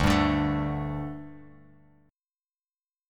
Dsus4 chord